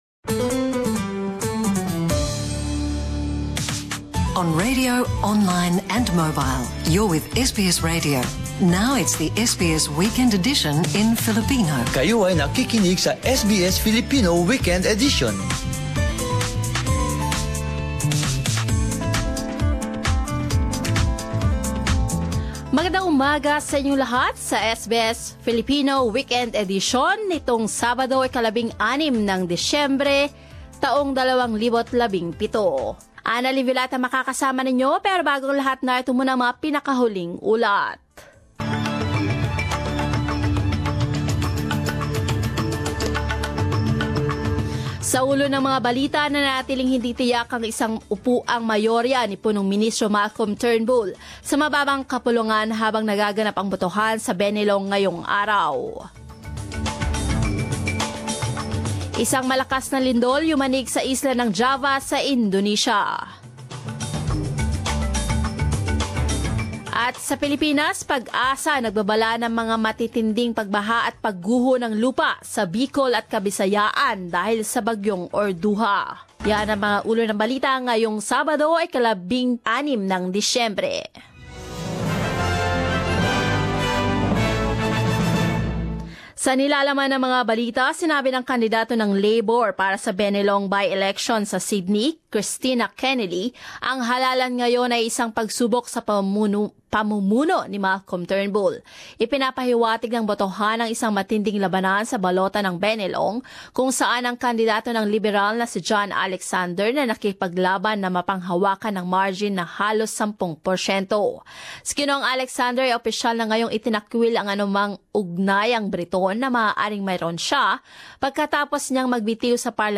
10 am News Bulletin in Filipino